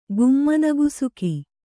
♪ gummana gusuka